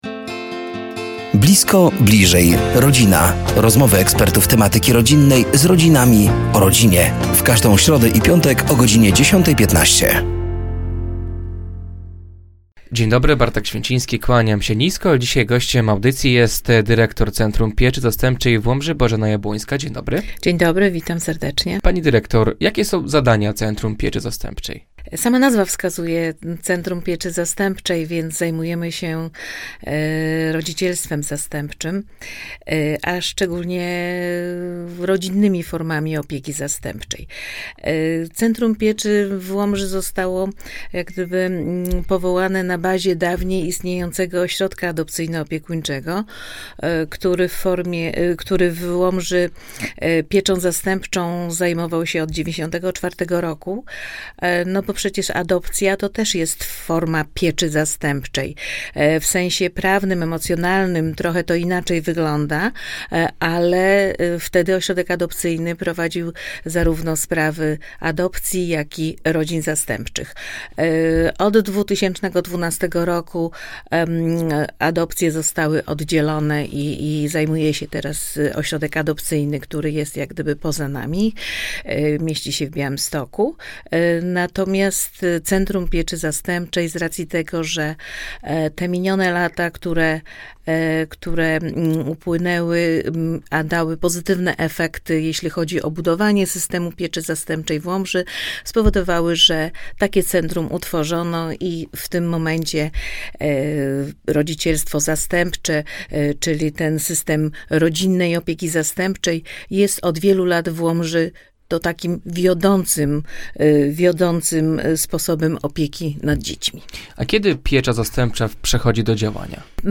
Do studia zaproszeni są eksperci w temacie rodziny i rodzicielstwa.